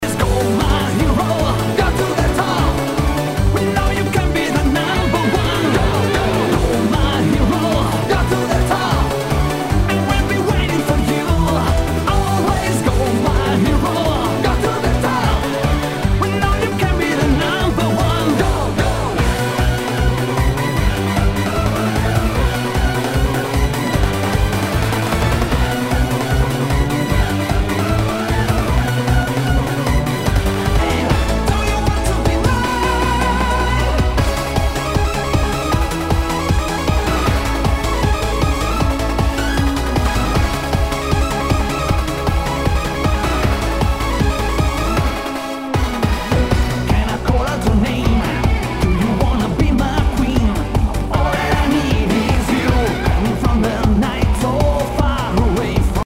HOUSE/TECHNO/ELECTRO
ナイス！ユーロビート！